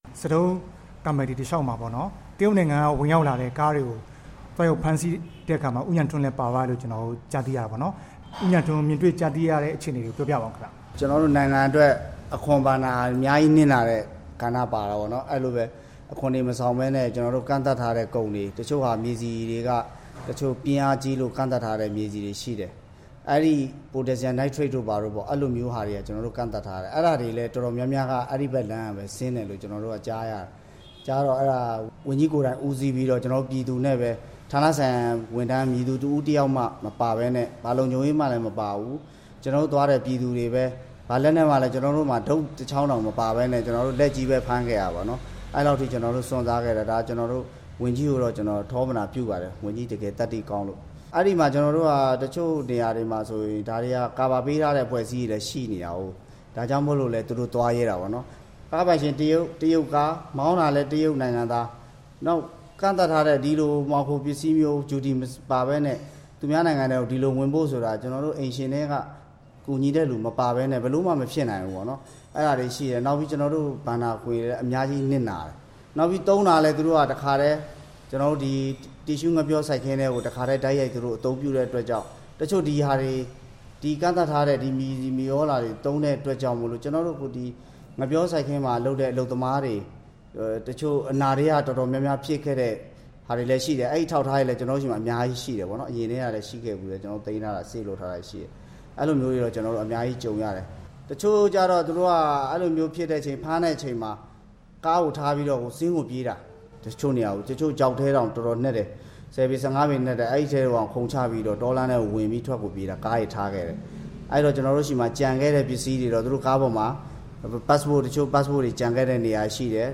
မေးမြန်းထားပါတယ်။